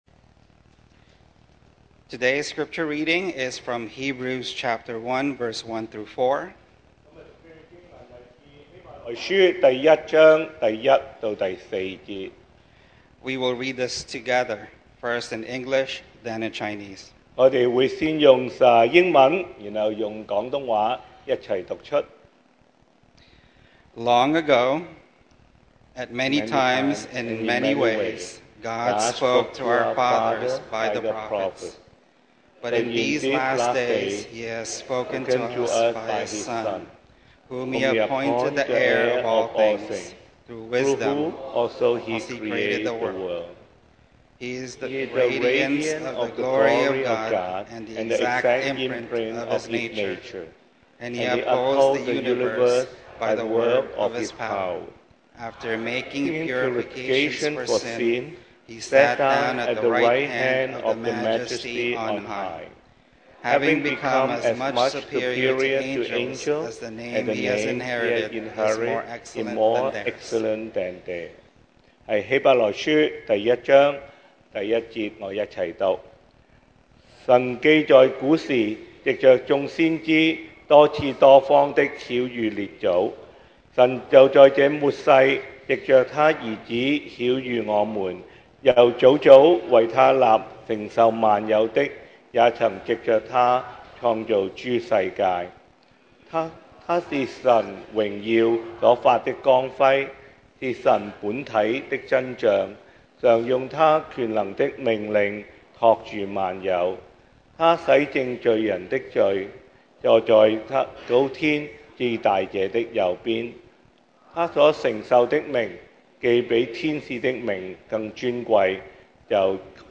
Series: 2024 sermon audios
Service Type: Sunday Morning